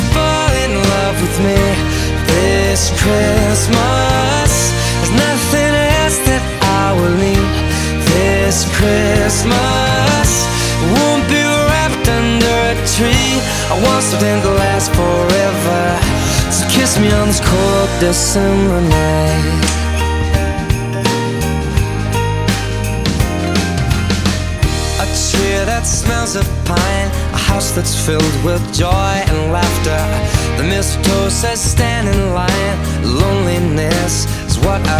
# Holiday